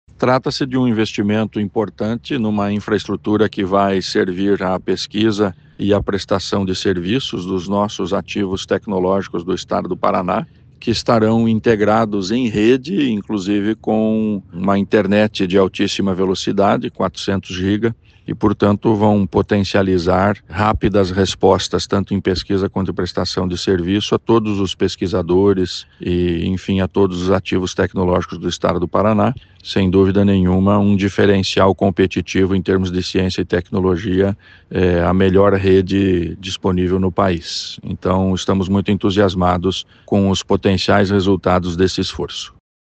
Sonora do secretário da Ciência, Tecnologia e Ensino Superior, Aldo Bona, sobre o lançamento do edital para criação de rede de supercomputadores